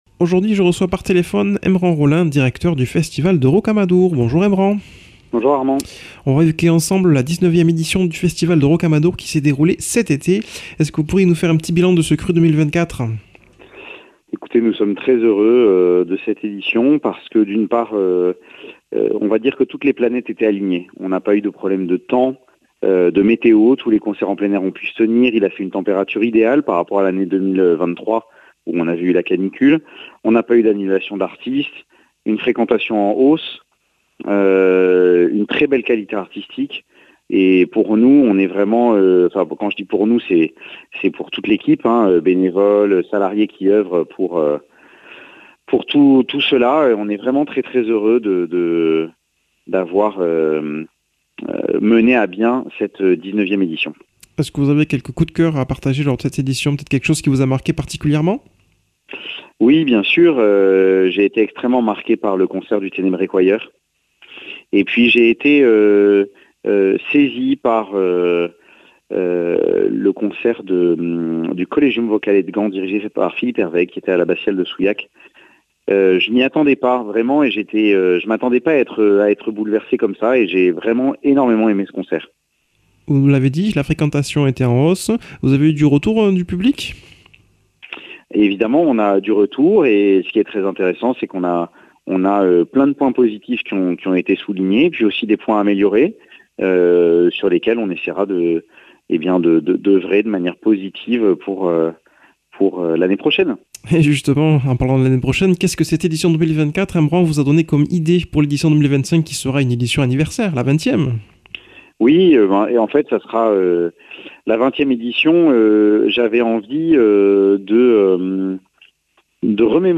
Présentateur